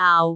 speech
syllable
pronunciation
aau6.wav